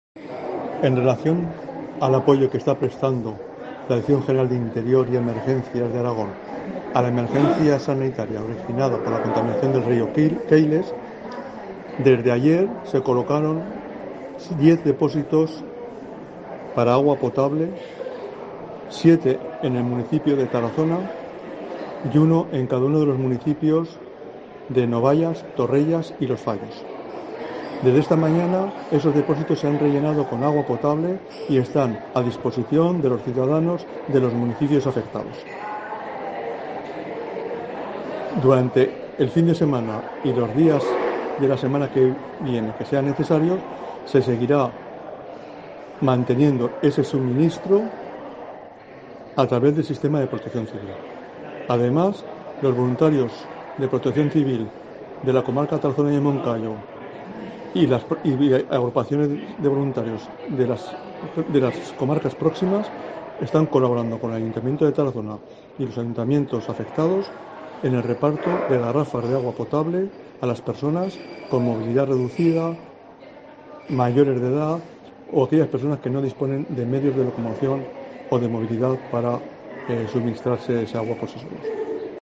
El Director General de Interior, Miguel Ángel Clavero, explica el sistema de depósitos de agua.